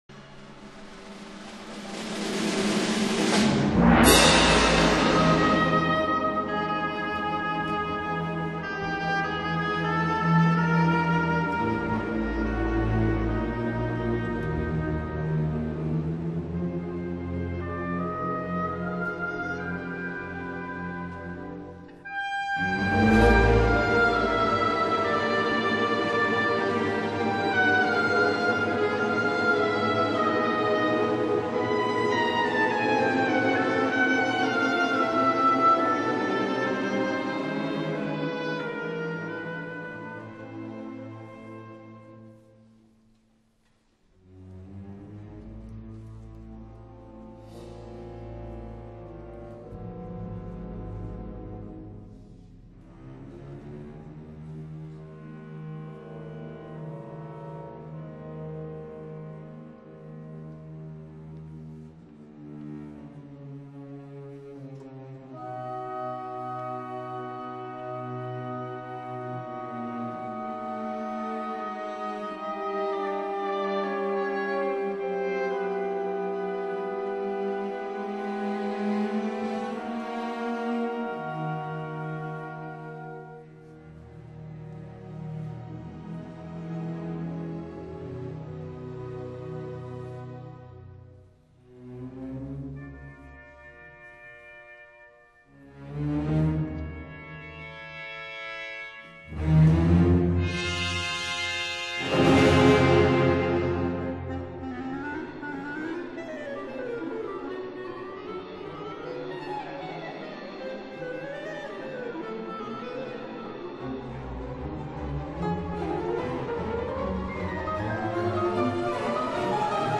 分辑：CD1-CD11 交响曲全集